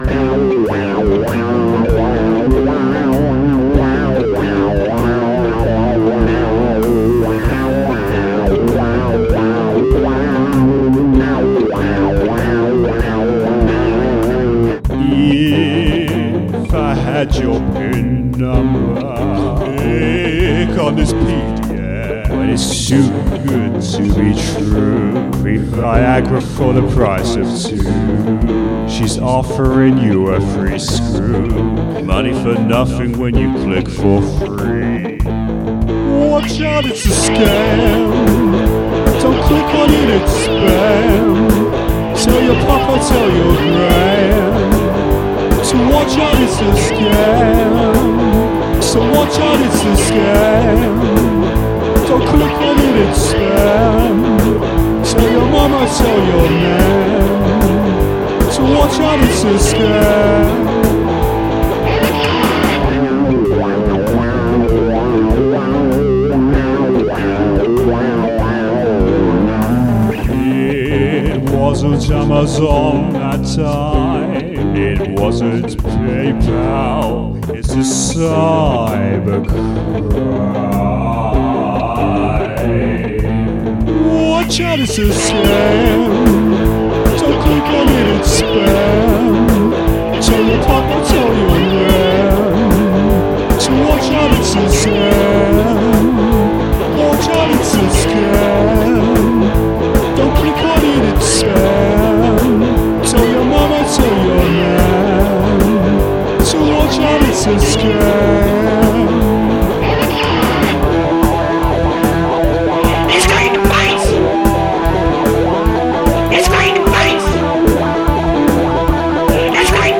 The guitar solo reflects the turmoil endured.